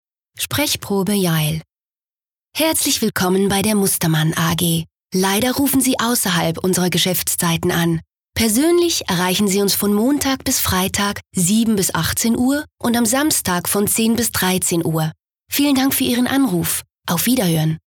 Telefonansage Hochdeutsch (CH)
Sprecherin mit breitem Einsatzspektrum.